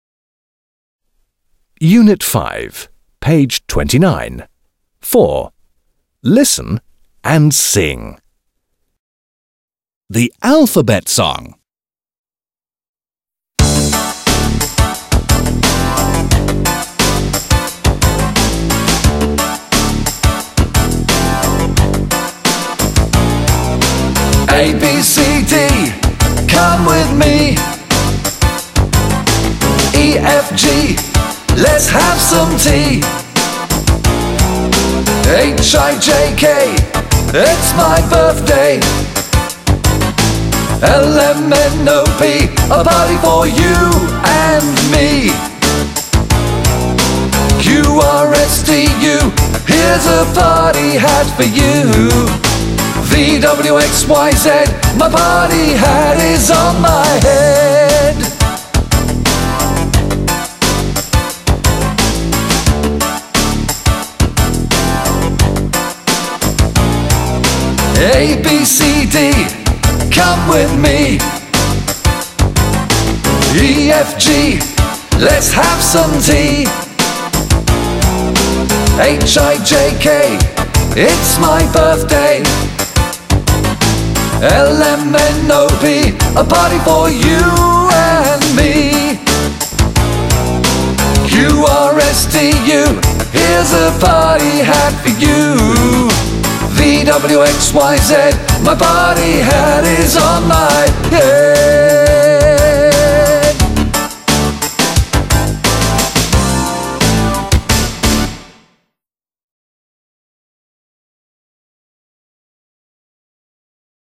Písnička